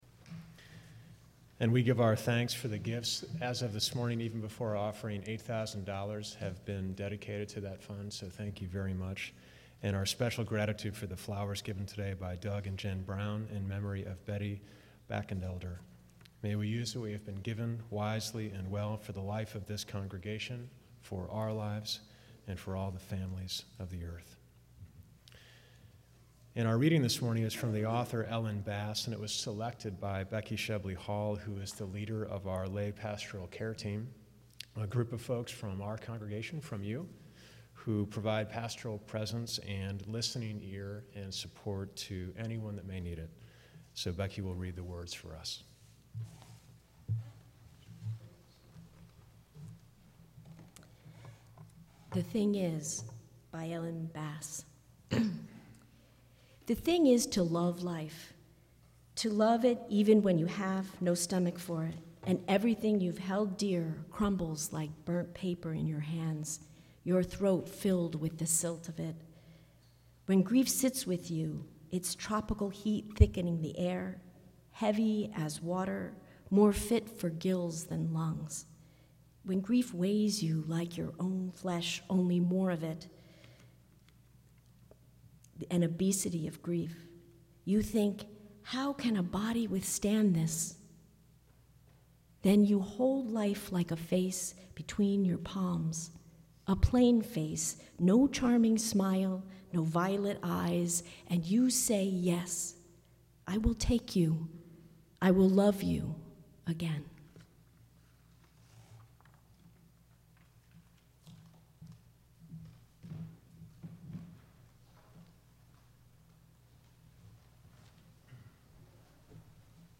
From Series: "2011 Sermons"
Sermon6_5_11.mp3